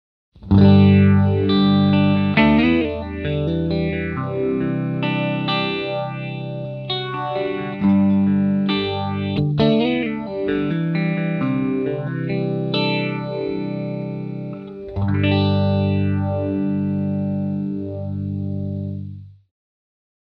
Vintage style Phase Shifter
SE-VPH makes histolical PHASE SHIFTER Sound effect.
You can adjust resonance to control SHARPNESS of Phase Shift sound.
Demo with Humbucker Pickup